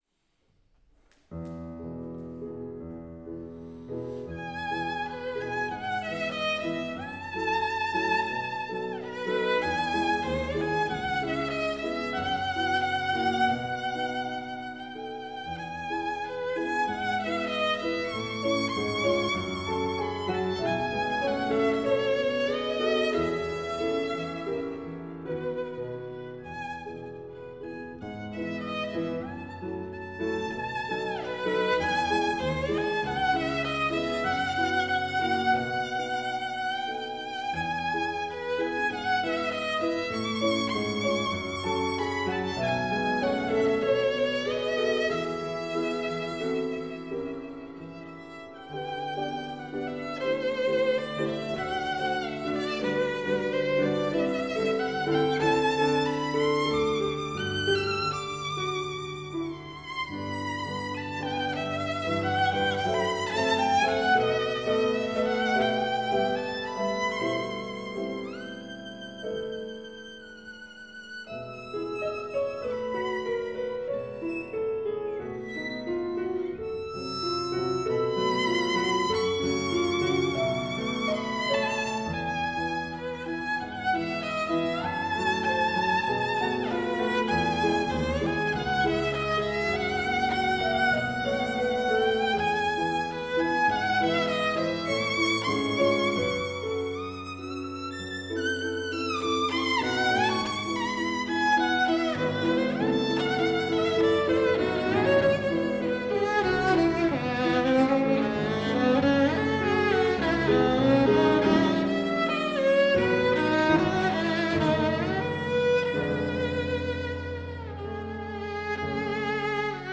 Geige B: